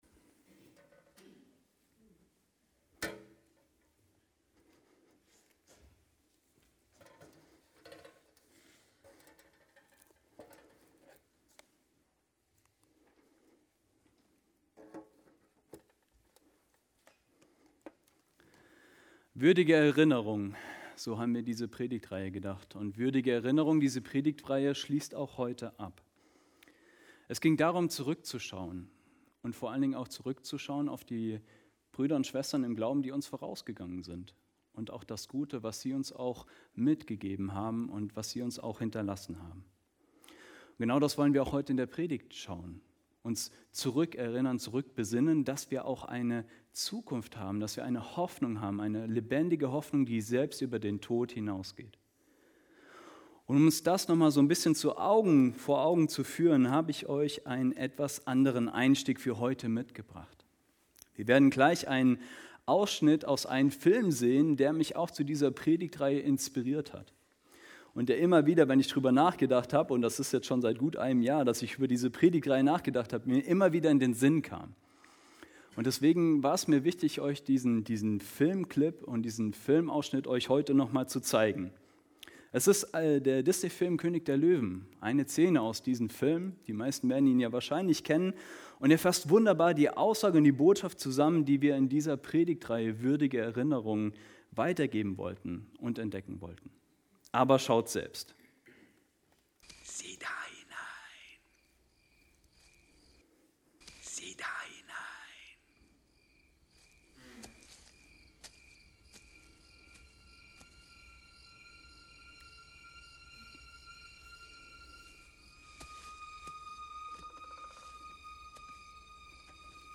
Predigt in der Evangelisch Freikirchlichen Gemeinde Herford
Predigten der EFG Herford